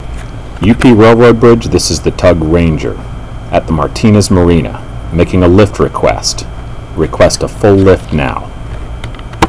The UP Railroad Bridge tender listens on VHF FM Channel 13.
Towing vessel